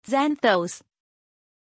Pronunția numelui Xanthos
pronunciation-xanthos-zh.mp3